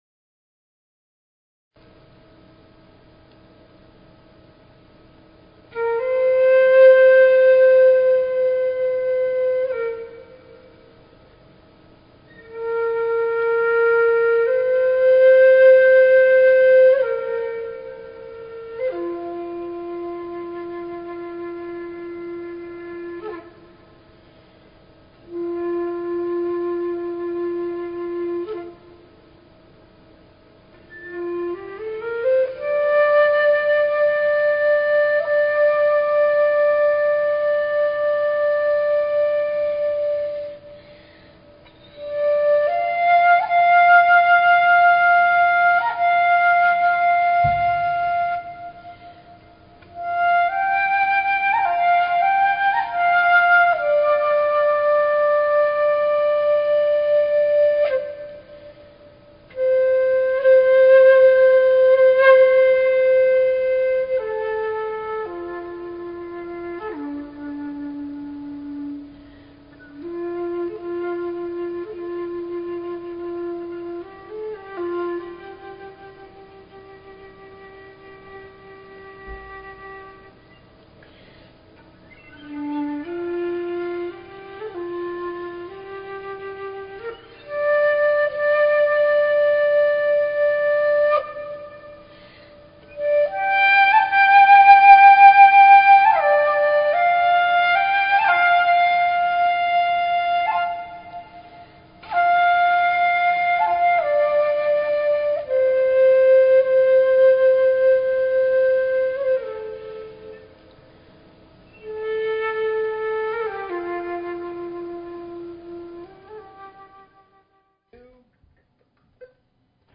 Talk Show Episode
The Elohim share how nodes of energy/frequency created space and time where none existed previously. As they communicated this information they connected up with the energies of listeners and at the end of the show, people had the opportunity to make silent requests.